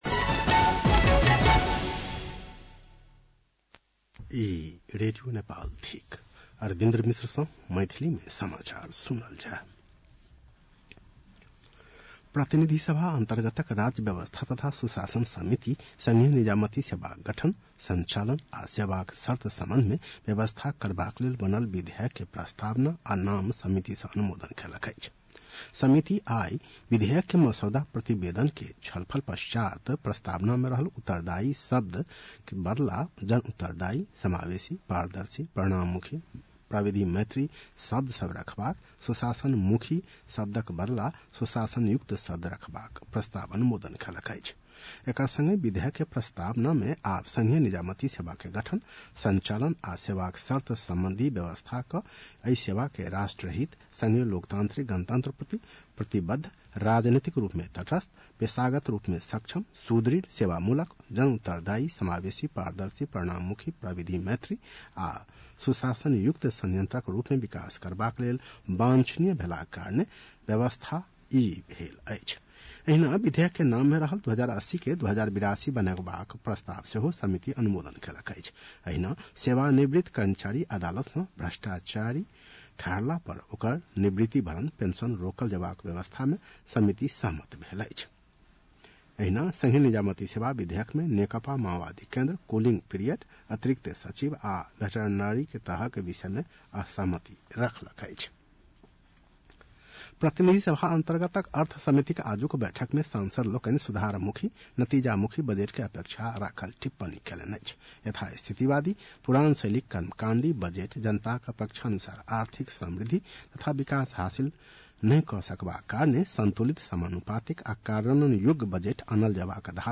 मैथिली भाषामा समाचार : १० वैशाख , २०८२
Maithali-news-1-10.mp3